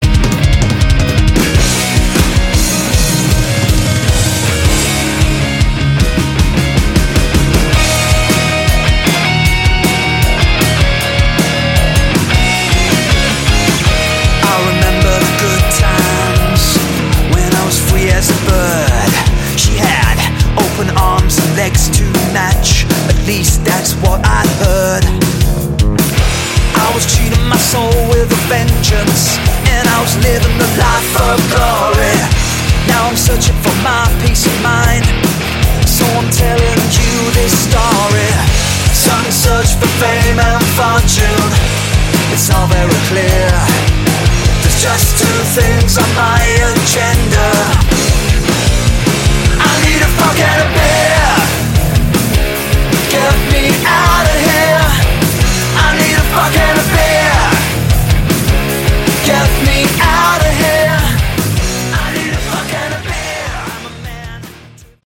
Category: Rock
vocals, guitar
drums, vocals
bass